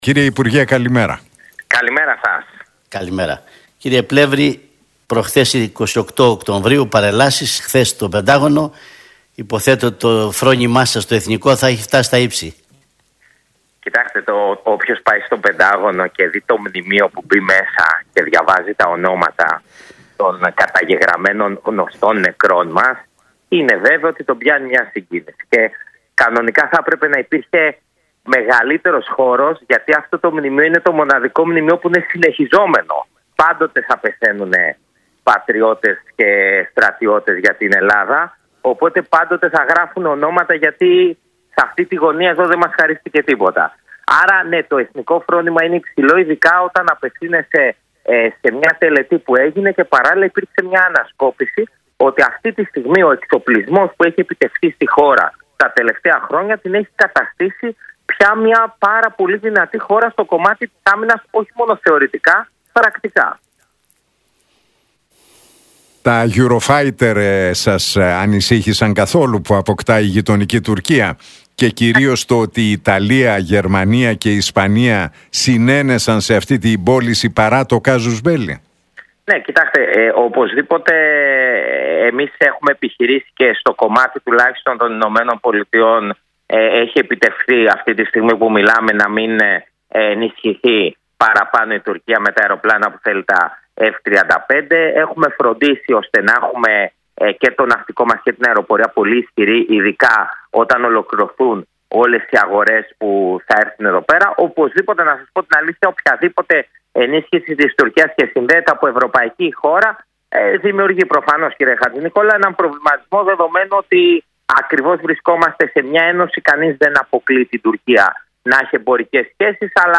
από τη συχνότητα του Realfm 97,8.